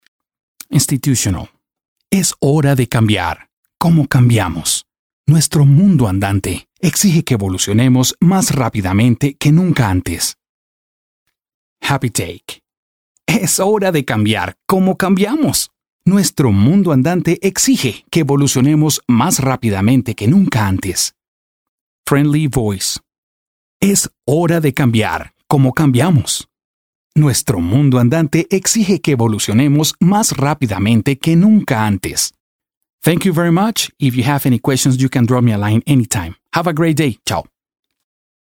I own a professional recording studio and I have several recording microphones such U-47, Sm7B and more.
Español male Spanish voiceover voice over locutor voice actor video audio corporativo jingles masculino voz
kolumbianisch
Sprechprobe: eLearning (Muttersprache):